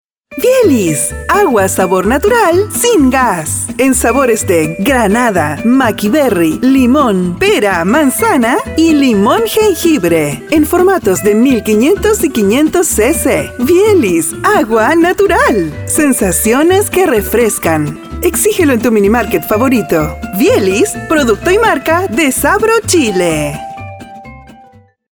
Promos
I have a versatile and clear Voice in Universal Neutral Spanish.
Young adult or adult female voice with a perfect diction, believable.
I own a professional audio recording studio, with soundproof booth included.